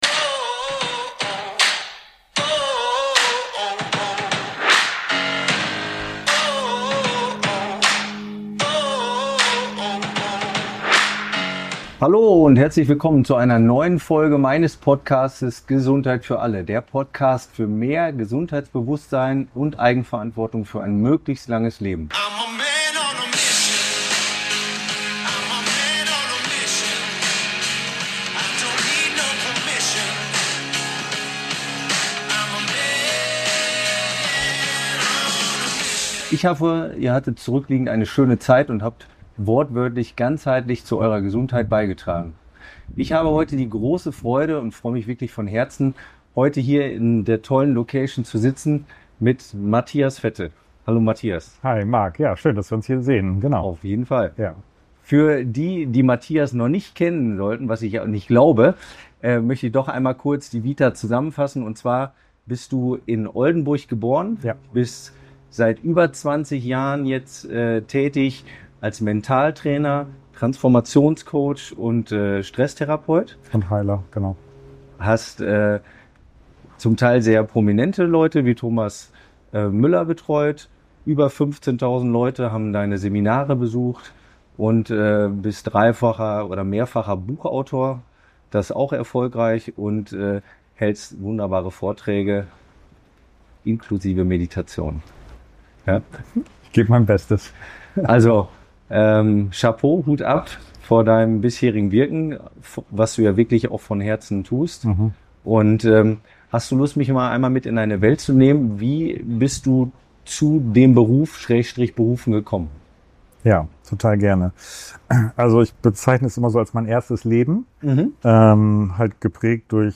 Wir sprechen darüber, wie Transformation auf Zellebene beginnen kann, warum Heilung immer ganzheitlich gedacht werden muss, und wie wir Zugang zu unserer eigenen inneren Kraft finden – jenseits von Konzepten und Konditionierungen. Ich bin dankbar für ein inspirierendes Gespräch über Authentizität, Energiearbeit und das Erwachen zu einem neuen Bewusstsein.